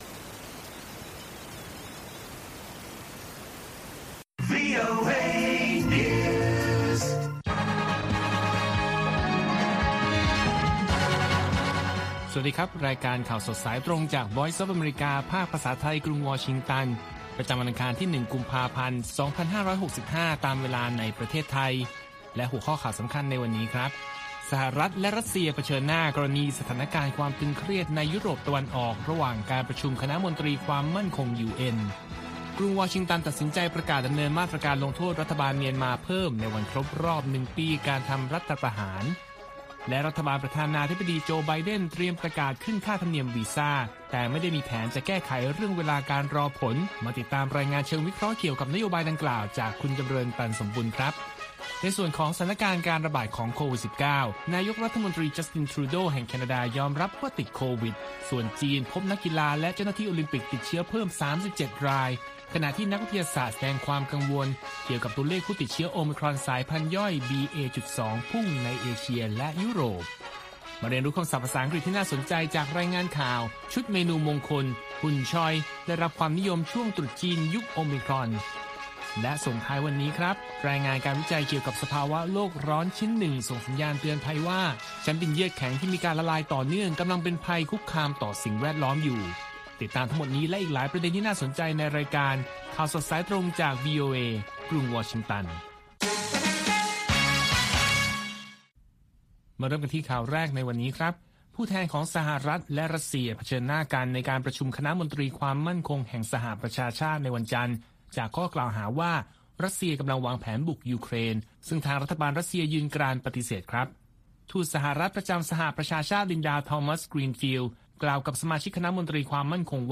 ข่าวสดสายตรงจากวีโอเอ ภาคภาษาไทย ประจำวันอังคารที่ 1 กุมภาพันธ์ 2565 ตามเวลาประเทศไทย